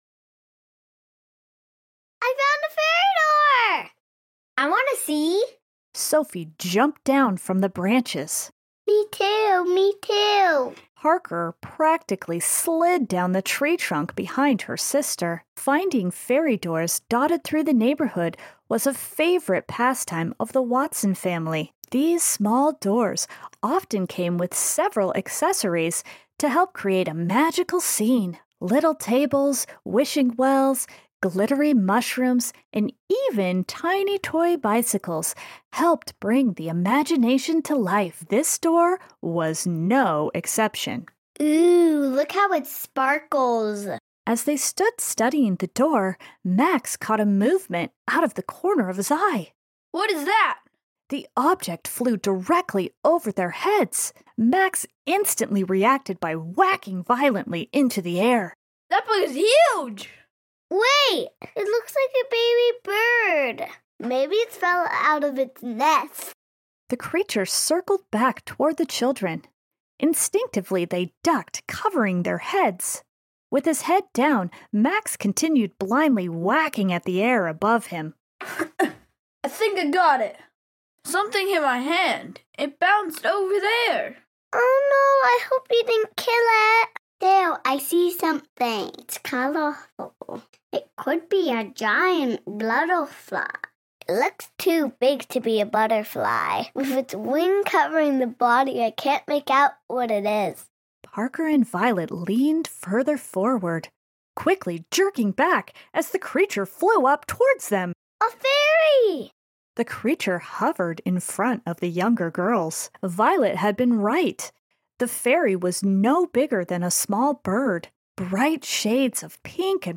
We Made some Audiobooks!!!